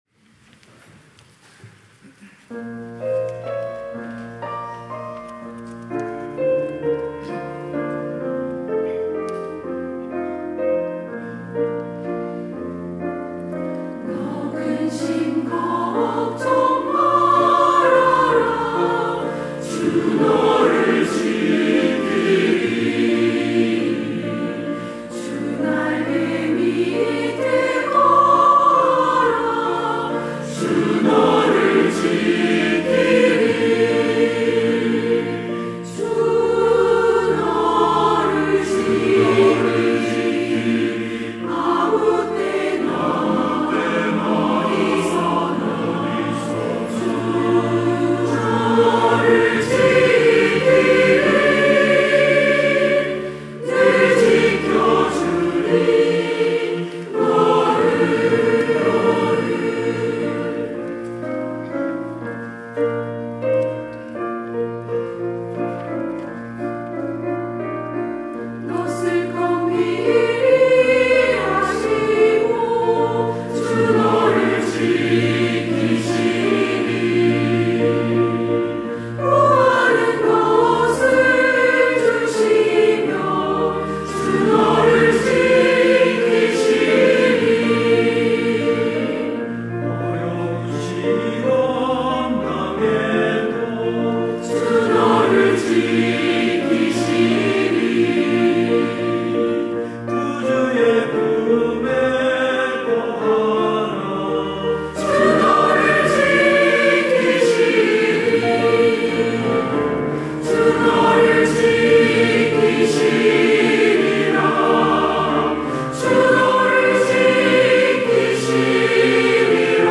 시온(주일1부) - 너 근심 걱정 말아라
찬양대